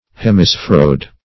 \Hem`i*sphe"roid\